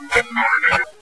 What follows is a huge collection of ghost voice recordings we have aquired there.
Some of the EVP's had to be amplified quite a bit....others were so strong and
This next pleasent speaking spirit tells us simply.....